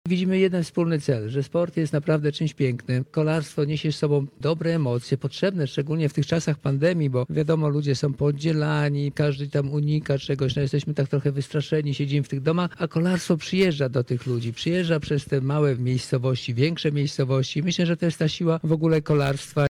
C. Lang – twierdzi dyrektor Tour de Pologne Czesław Lang.